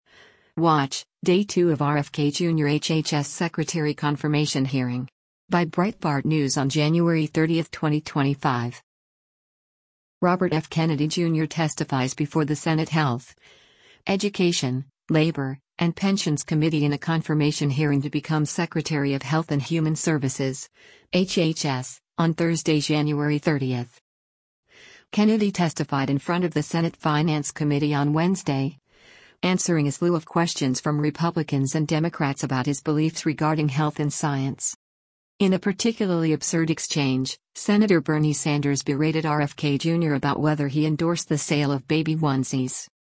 Robert F. Kennedy Jr. testifies before the Senate Health, Education, Labor, and Pensions Committee in a confirmation hearing to become Secretary of Health and Human Services (HHS) on Thursday, January 30.